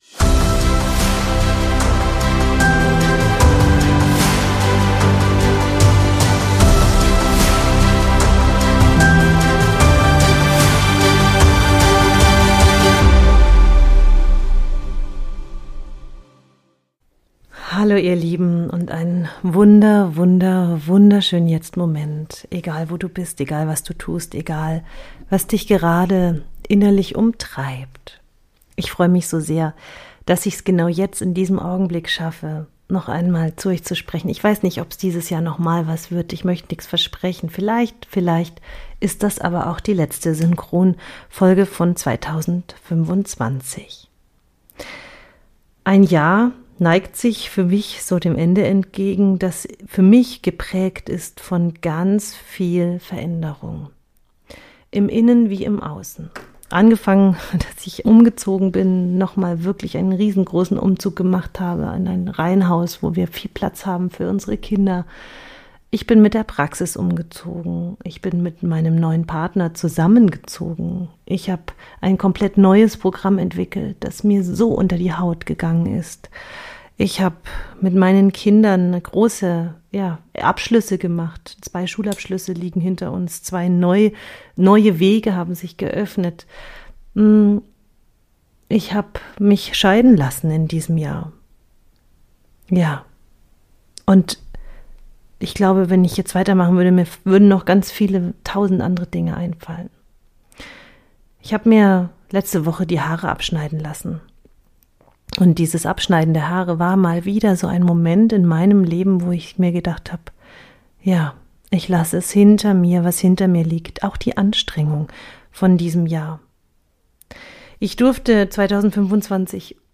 Diese Folge ist eine leise, kraftvolle Jahresabschlussfolge.